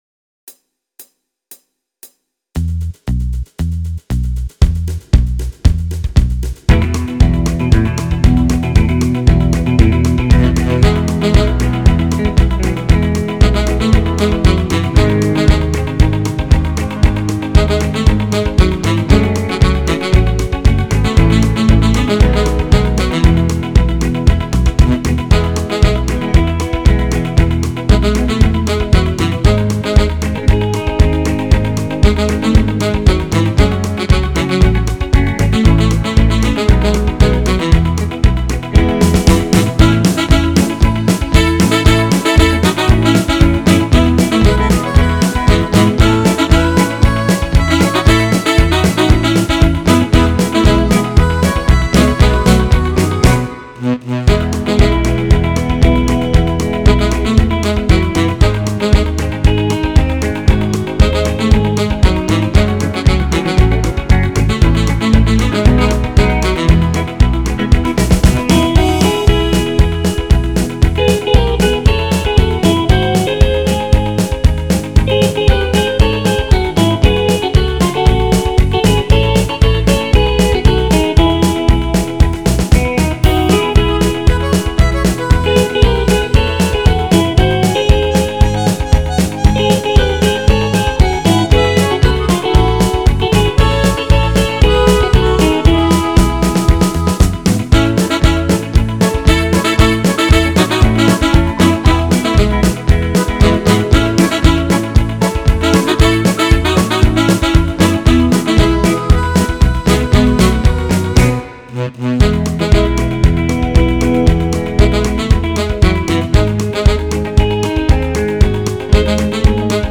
[On the Country List]